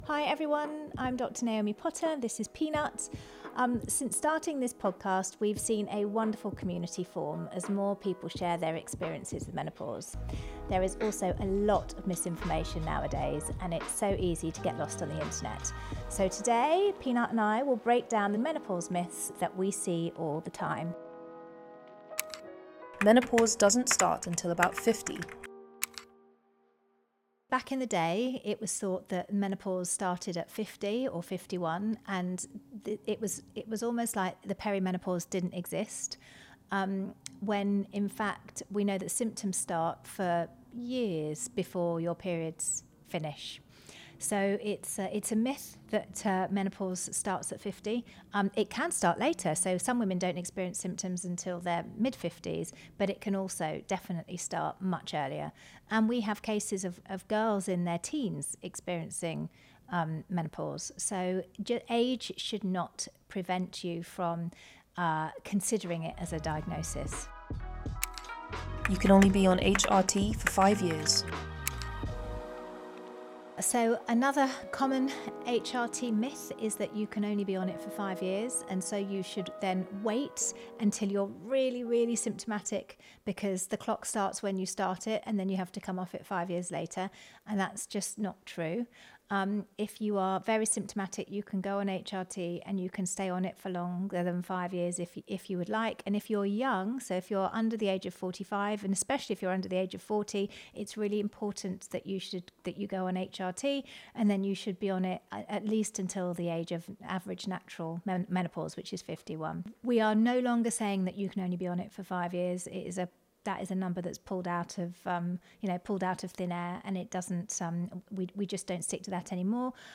Part open-clinic, part talk show, this podcast is for all women seeking insight, reassurance, empathy, answers and laughter while navigating midlife.
Together, they unpick the physical, mental and emotional changes that so many of us relate to. Guests share their personal stories, open up about their difficulties, and laugh about some of the more bewildering challenges that come with mid-life.